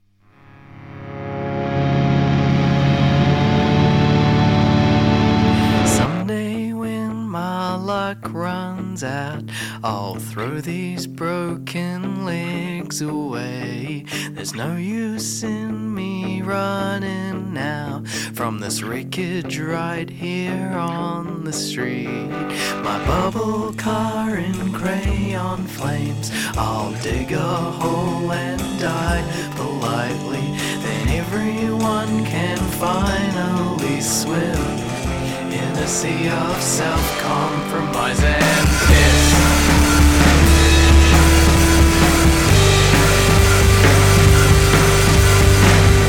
Get the scoop on local, national and international current affairs with our daily news bulletin.